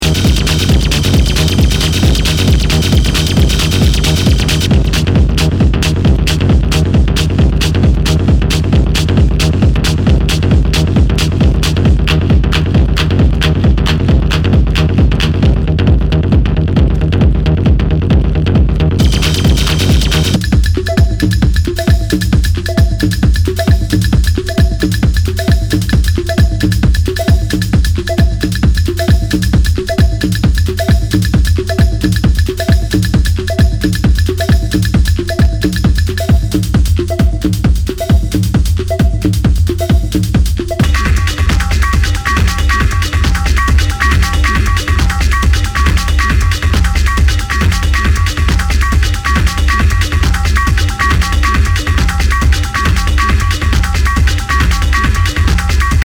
HOUSE/TECHNO/ELECTRO
ナイス！ミニマル！
類別 TECHNO